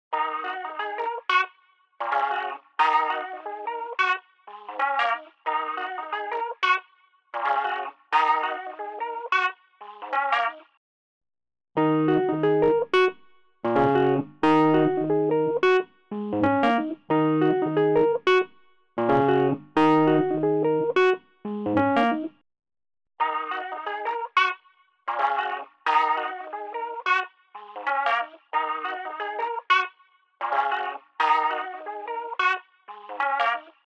渦巻くクラシック・ロータリーサウンド
Rotary Mod | Wurtilizer | Preset: Old Time Radio
Rotary-Eventide-Wurlitzer-Old-Tyme-Radio.mp3